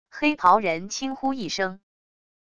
黑袍人轻呼一声wav音频